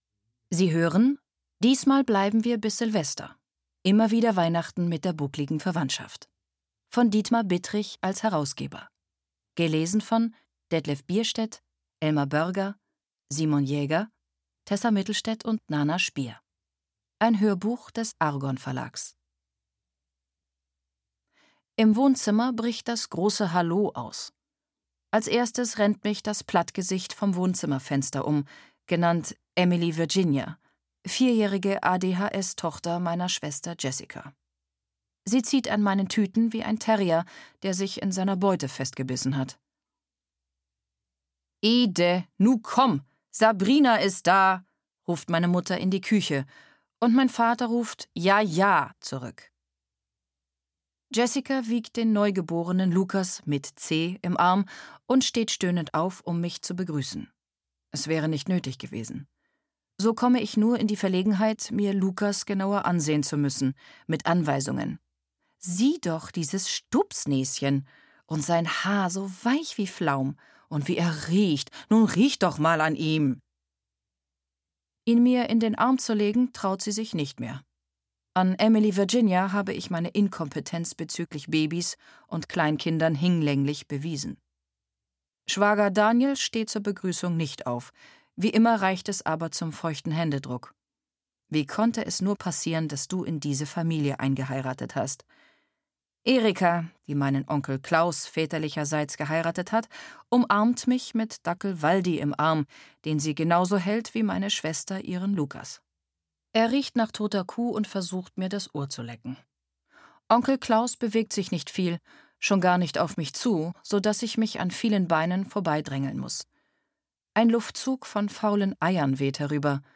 Seine volle und markante Stimme erzeugt eine Spannung, der man sich nicht entziehen kann.
Seine junge, tiefe Stimme macht das Gehörte unmittelbar erlebbar.
Mit seiner markanten, eindringlichen Stimme macht er alle Hörbücher zu etwas Besonderem.